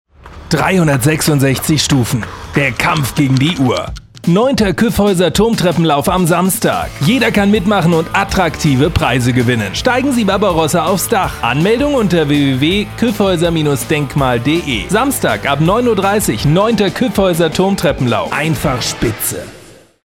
Sprecher deutsch.
Sprechprobe: Werbung (Muttersprache):
german voice over talent